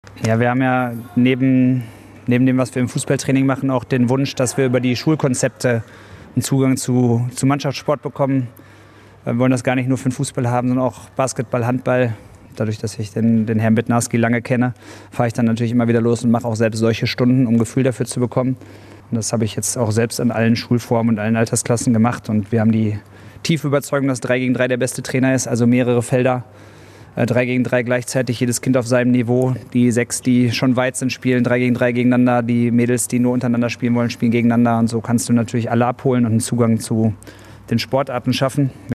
o-ton-hannes-wolf.mp3